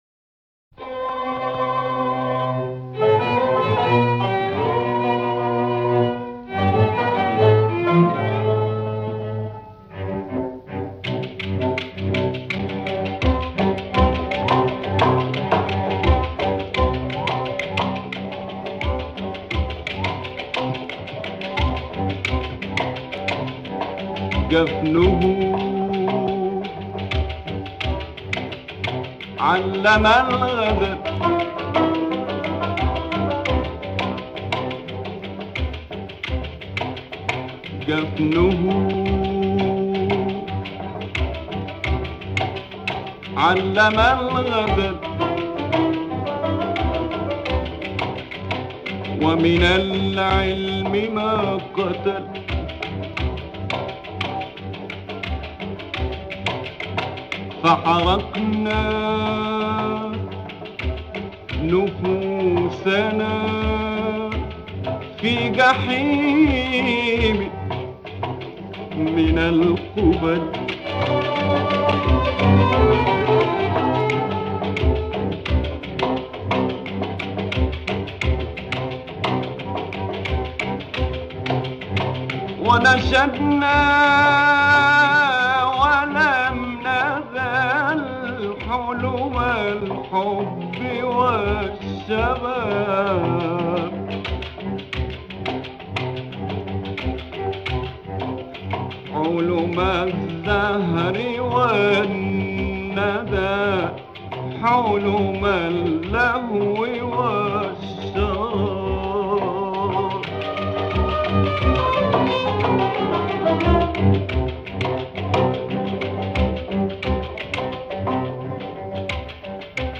Importante cantor e compositor egípcio.